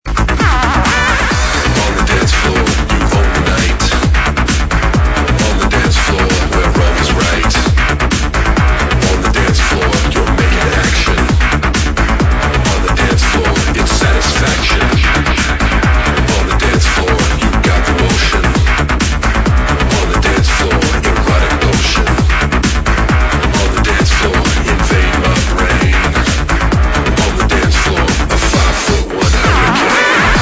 House track - male vocals "on the dancefloor"